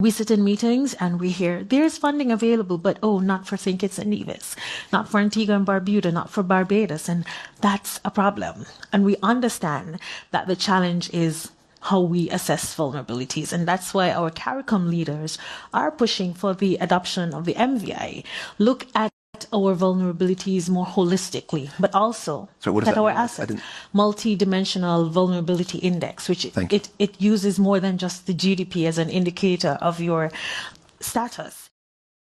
While engaged in a panel discussion Dr. Clarke highlighted the challenges to accessing green financing such as concessional loans and grants for Small Island Developing States (SIDs) and spotlighted the adaptation of the Multidimensional Vulnerability Index (MVI).